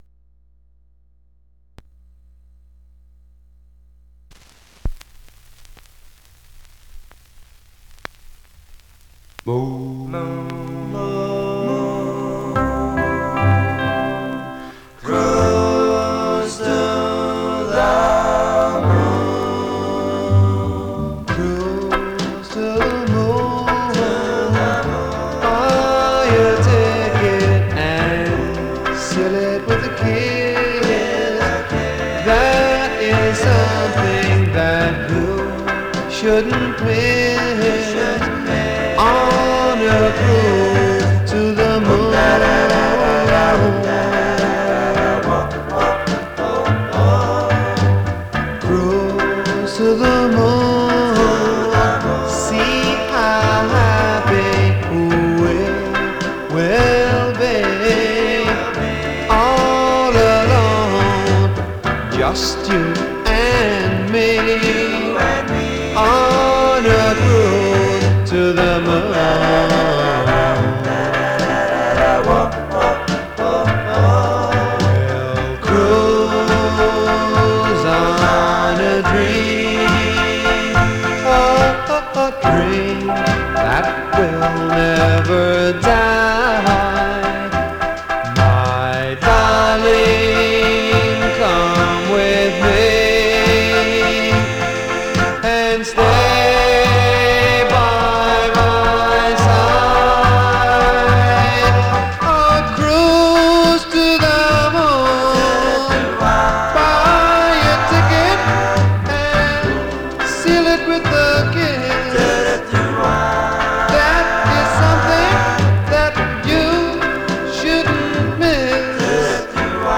Mono
Vocal Group